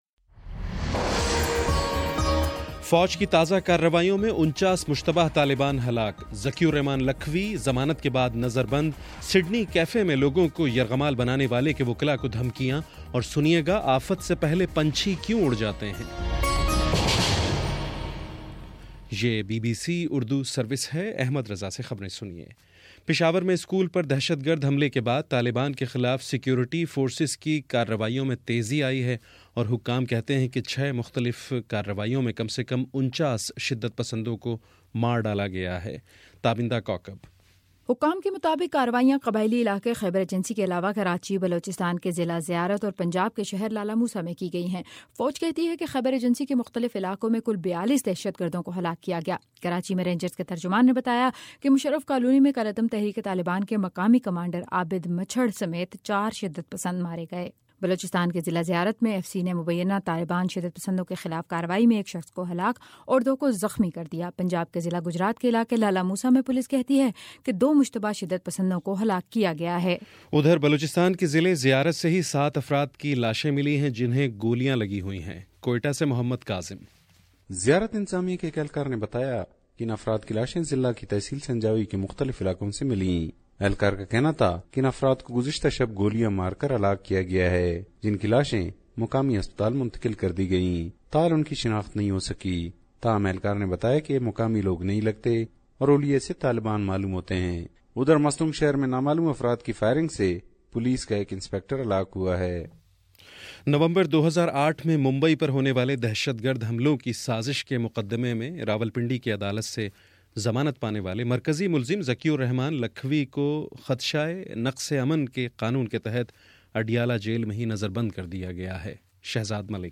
دسمبر 19 : شام چھ بجے کا نیوز بُلیٹن